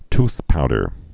tooth·pow·der
(tthpoudər)